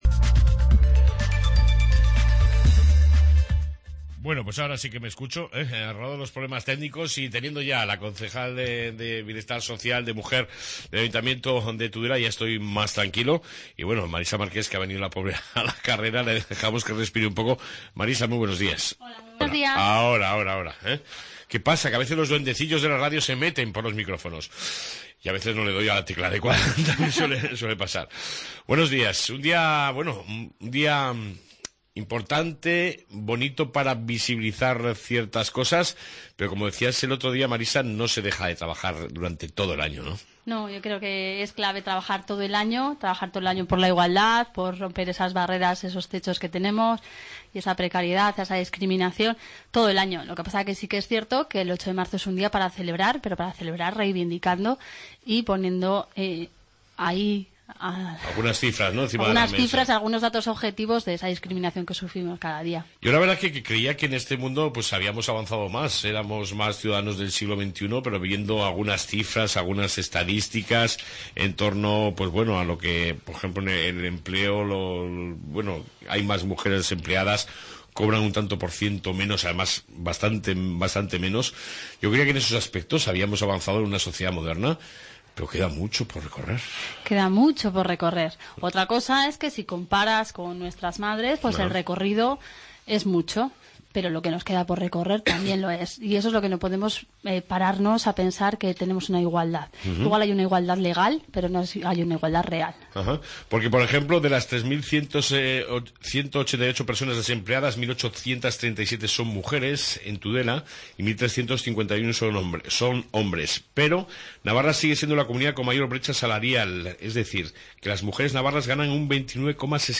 AUDIO: En este 08 de Marzo entrevista con la concejal Marisa Marqués